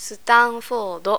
sutanfo:do', 'C++' becomes 'shi:pulasupulasu', and 'raspberry ice cream' becomes '
sutanfodo.wav